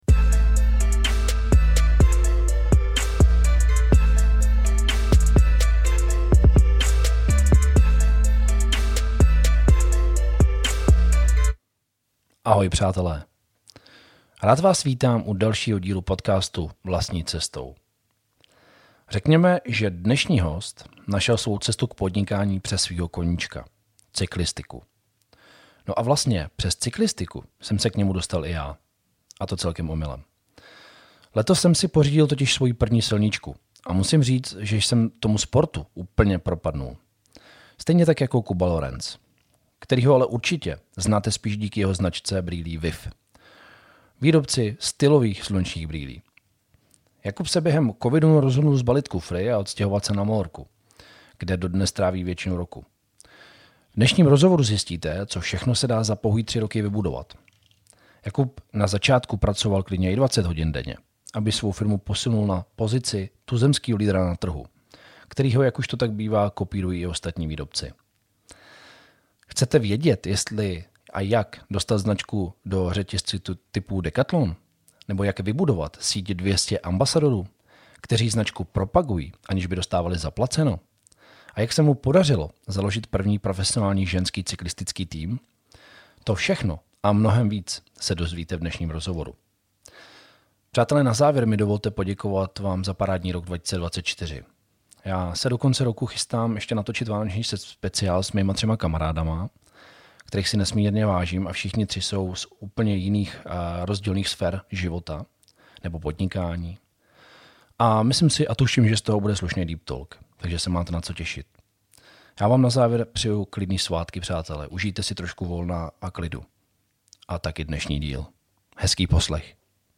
Každý z hostů má cca 45minut času na mé dotazy, závěrem jsem si na pódium pozval všechny najednou a prostor pro dotazy dostali diváci v sále.